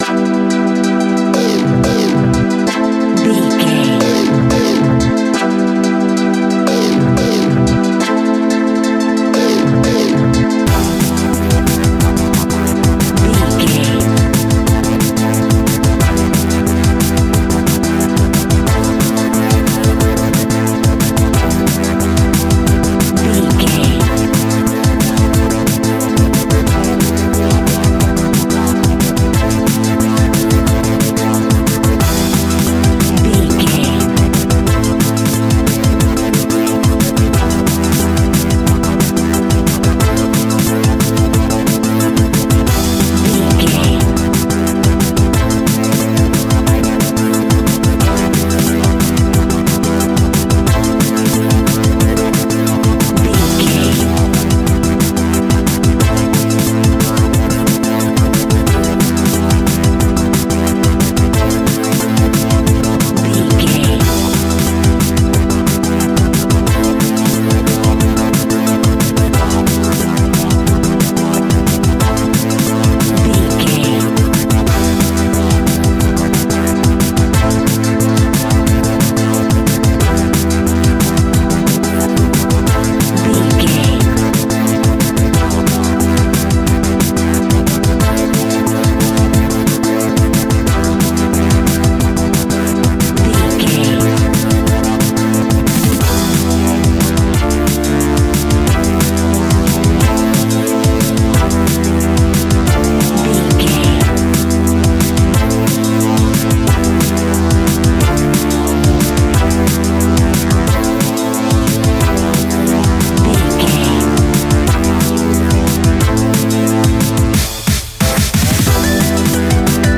Aeolian/Minor
Fast
hypnotic
industrial
frantic
aggressive
dark
drum machine
synthesiser
electronic
sub bass
instrumentals
synth leads